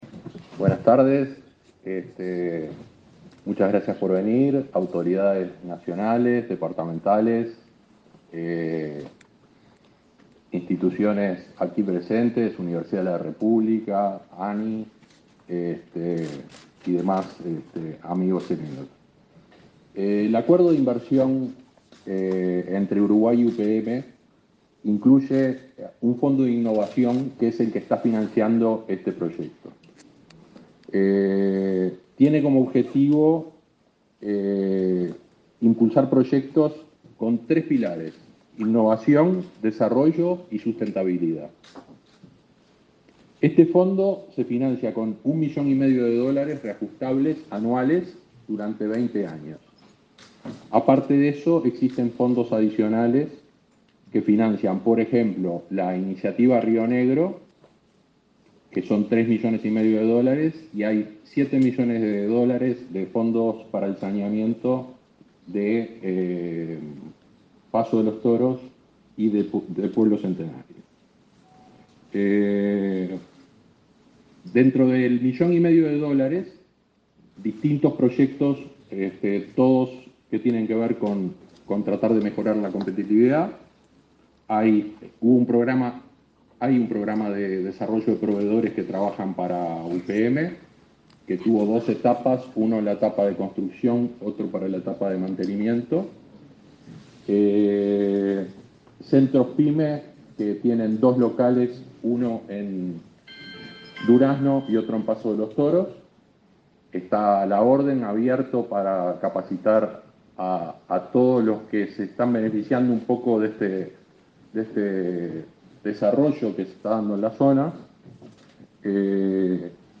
Conferencia de prensa por el lanzamiento del Centro Tecnológico Forestal Maderero